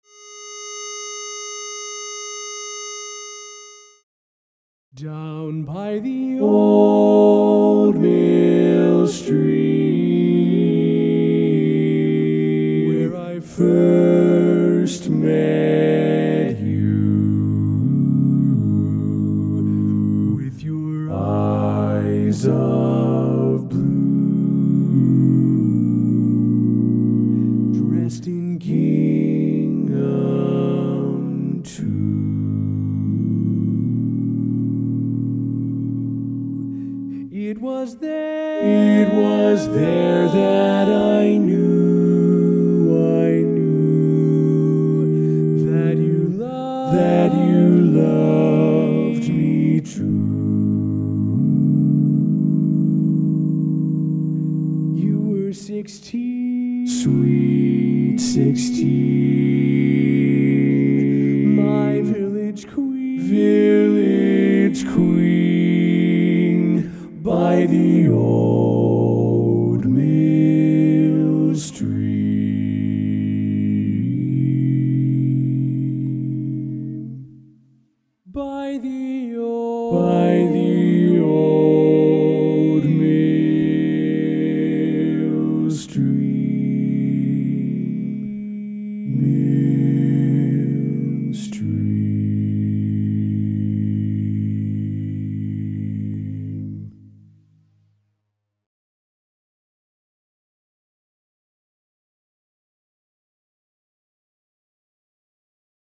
Barbershop
Bari